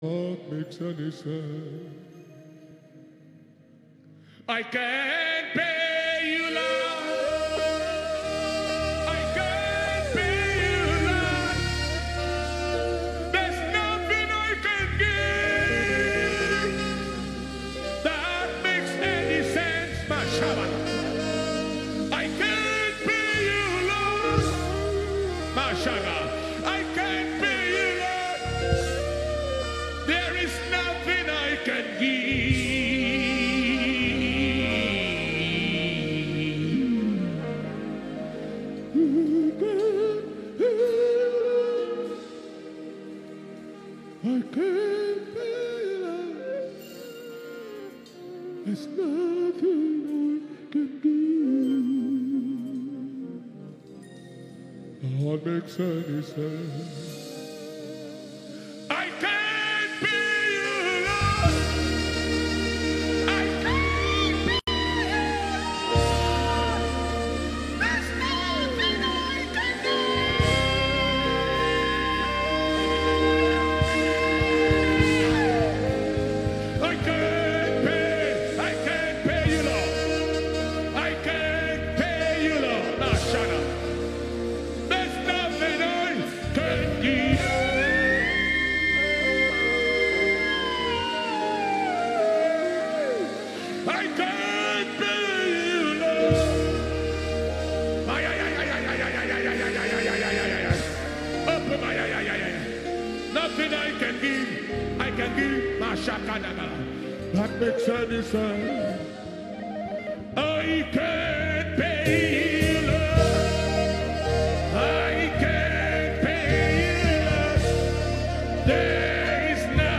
September 2020 blessing sunday.